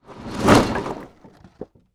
DEMOLISH_Wood_Fall_stereo.wav